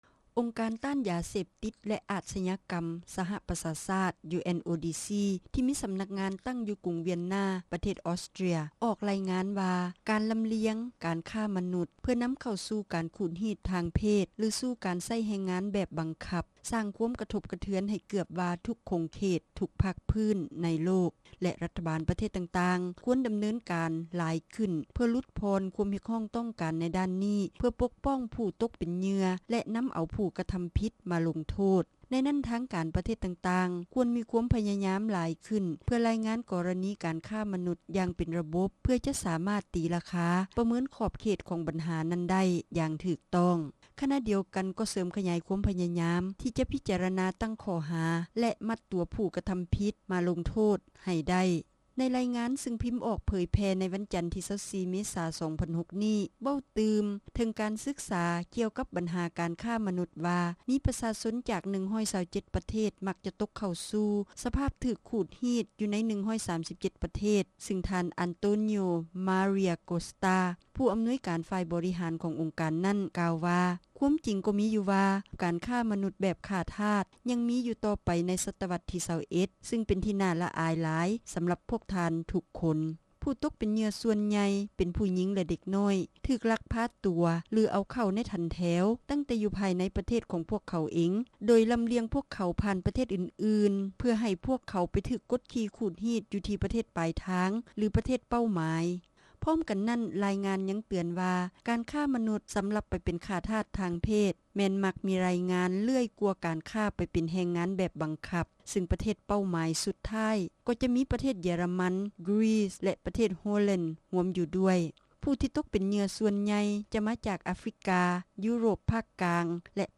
ຣາຍງານສະບັບໃໝ່ຂອງຫ້ອງການສະຫະປະຊາຊາດດ້ານບັນຫາຢາເສບຕິດແລະອາດສະຍາກໍາ — ຂ່າວລາວ ວິທຍຸເອເຊັຽເສຣີ ພາສາລາວ